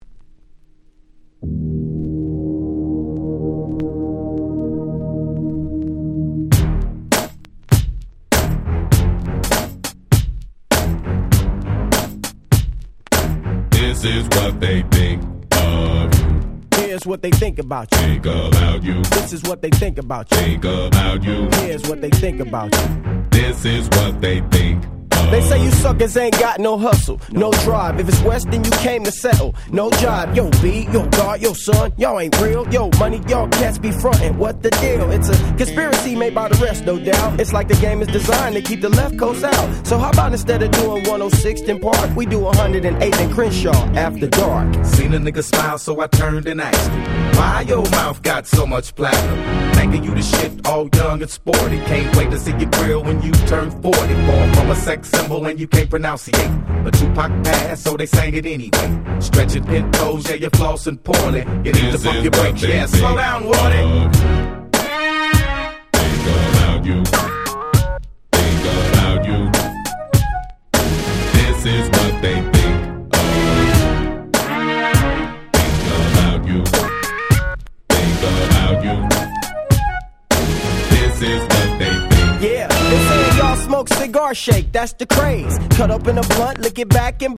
03' Smash Hit West Coast Hip Hop !!
コレが悪いはずも無く最高のウエッサイバンギンに！！
「ウエッサイってこういうのだよね！」って感じの教科書通りな1曲です！！